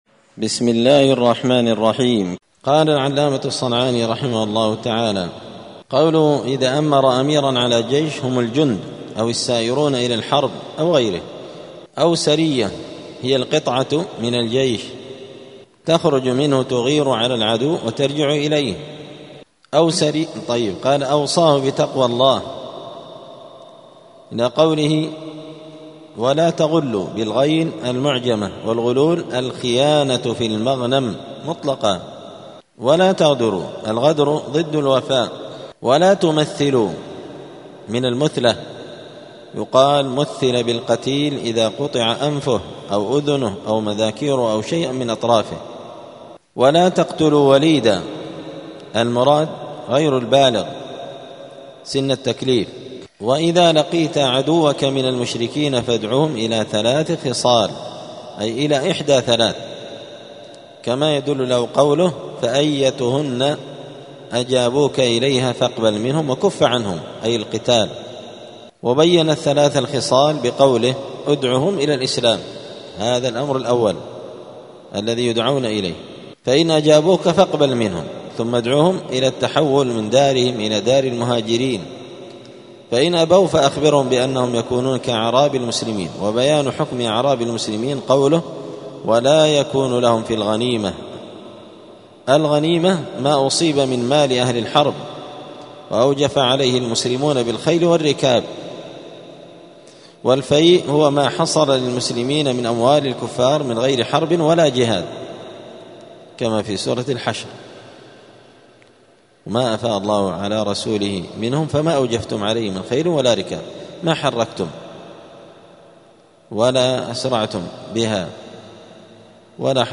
*الدرس السابع (7) {باب ﻭﺻﺎﻳﺎ ﺭﺳﻮﻝ اﻟﻠﻪ ﻷﻣﺮاء اﻟﺠﻴﻮﺵ ﻭﺃﺧﺬ اﻟﺠﺰﻳﺔ ﻣﻦ ﻣﺸﺮﻛﻲ اﻟﻌﺮﺏ}*
دار الحديث السلفية بمسجد الفرقان قشن المهرة اليمن